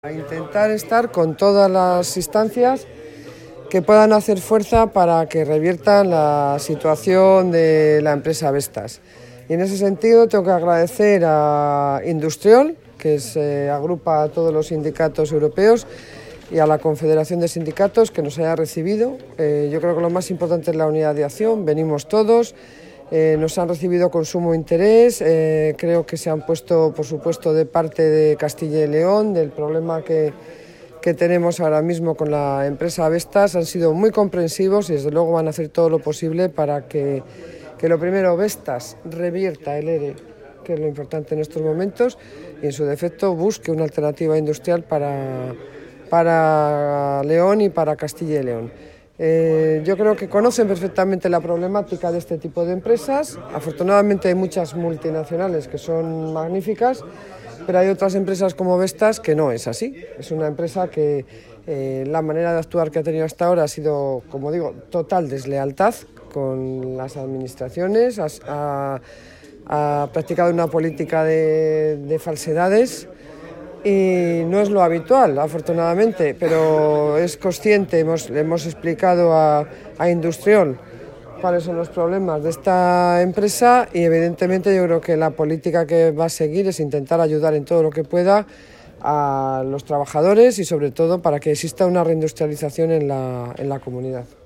Declaraciones de la consejera de Economía y Hacienda al término de la reunión con IndustriALL-CES sobre Vestas
Declaraciones de la consejera de Economía y Hacienda, Pilar del Olmo, al término de la reunión entre representantes de la Fundación Anclaje y el Comité de Empresa de Vestas con IndustriALL-CES, celebrada en la sede del Parlamento Europeo, en Bruselas (Bélgica).